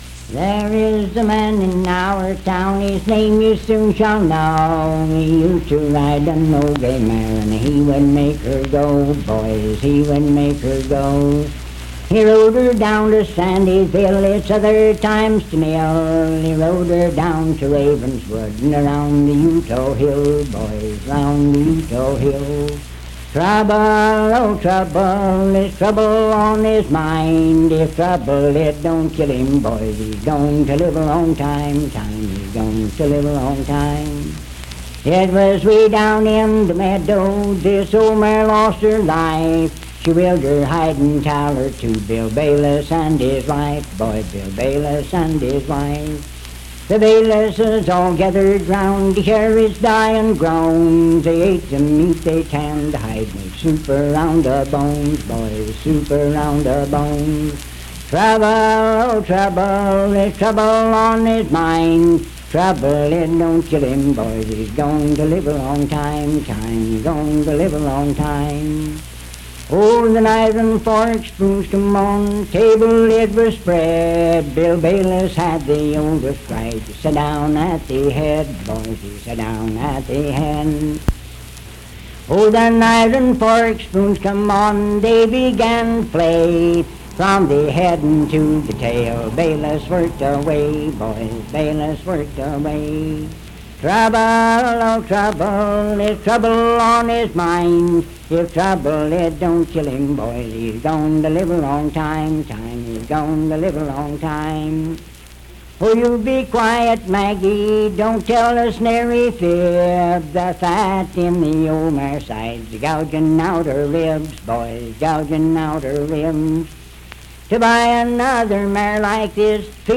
Unaccompanied vocal music
Verse-refrain 9(5w/R)&R(5).
Performed in Sandyville, Jackson County, WV.
Voice (sung)